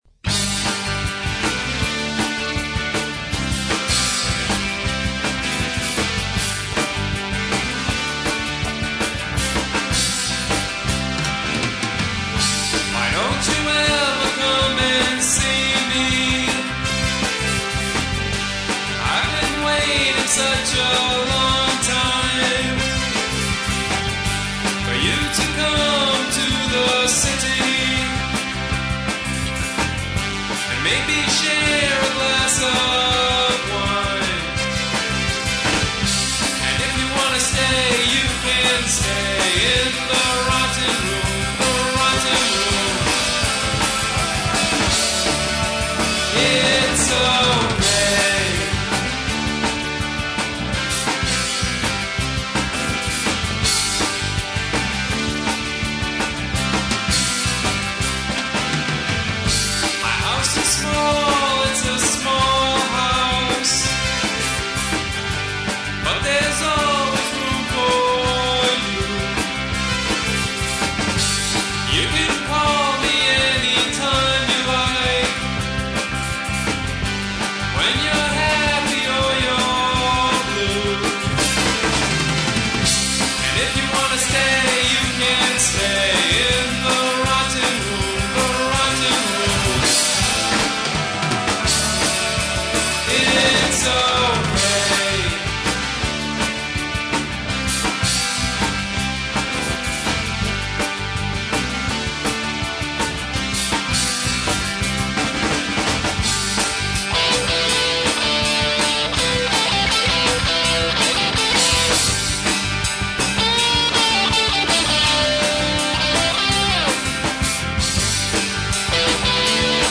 Keyboards, Vocals
Drums, Vocals
Bass, Vocals
Guitar, Lead Vocals